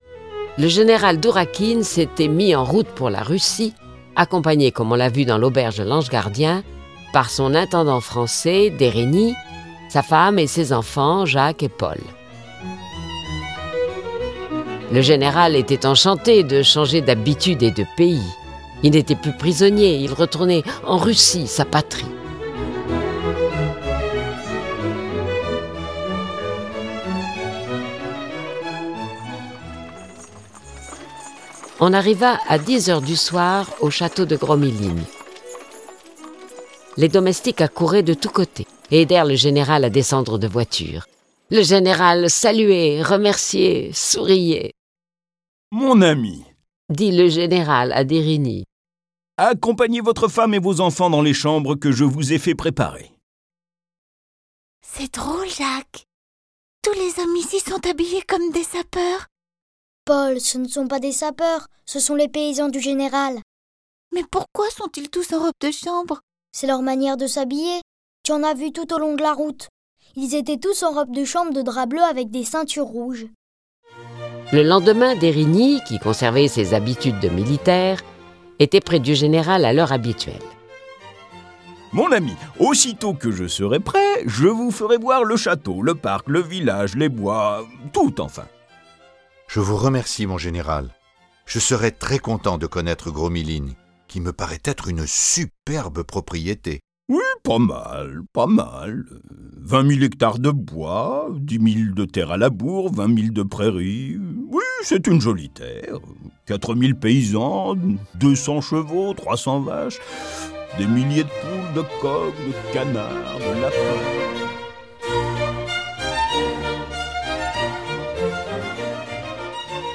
Diffusion distribution ebook et livre audio - Catalogue livres numériques
Cette version sonore des aventures du général Dourakine et de ses amis est animée par dix voix et accompagnée de près de trente morceaux de musique classique.